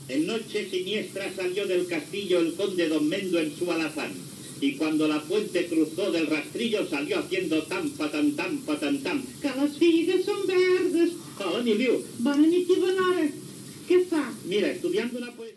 Toresky recita a Miliu un fragment de "La venganza de don Mendo", obra teatral de Pedro Muñoz Seca.
Entreteniment